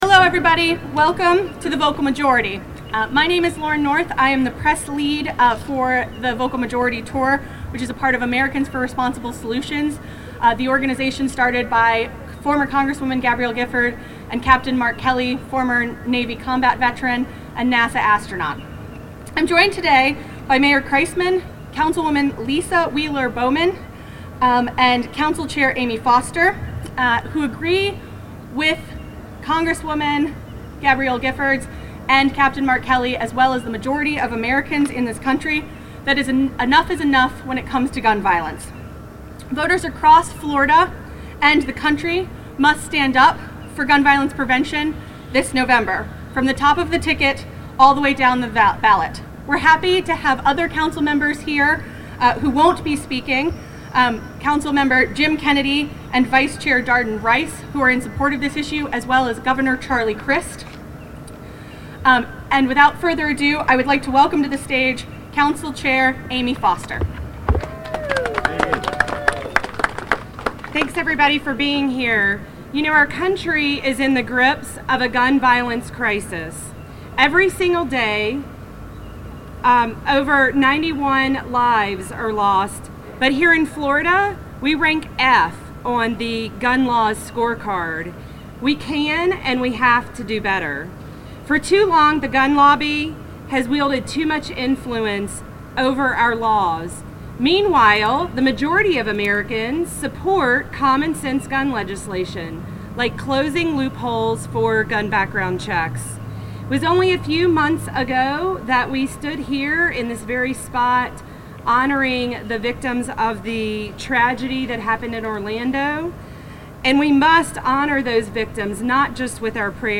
Vocal Majority Press Conference St. Petersburg 9-29-16